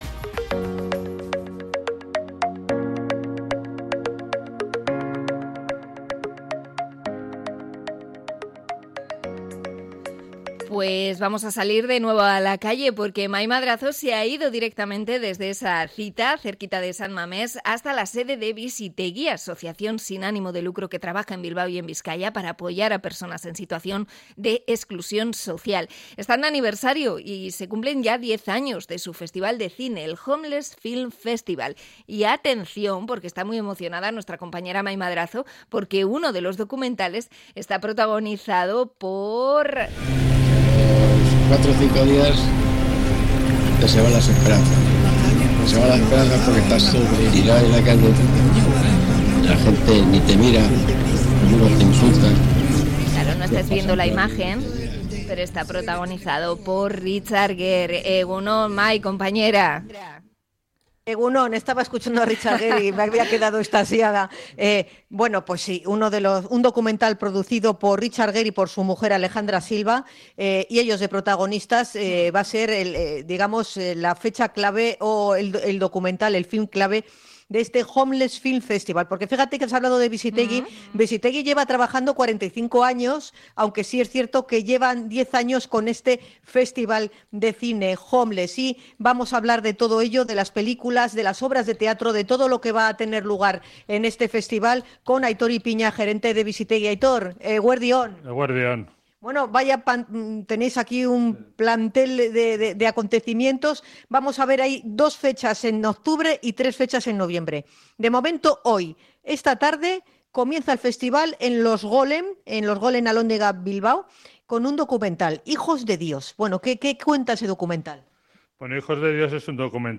Entrevista sobre el festival de cine Homeless Film Festival de Bizitegi